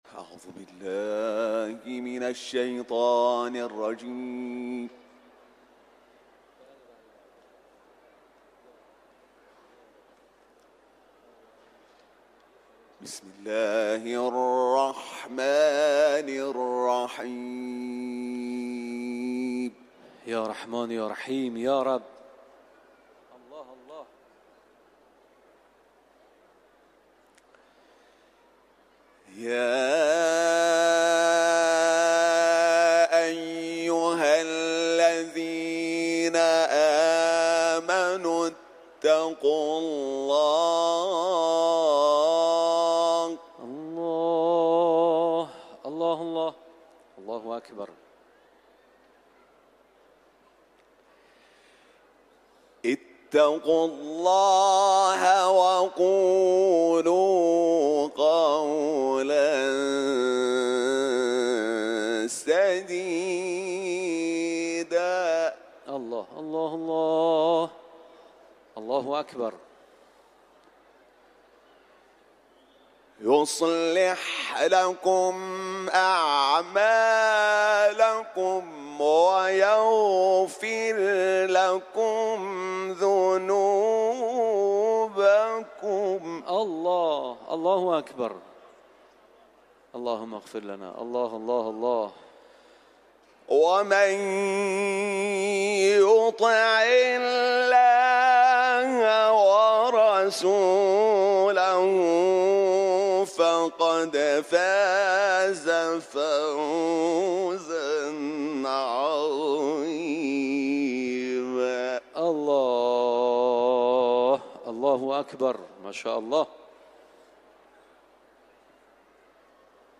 Kur’an-ı Kerim tilaveti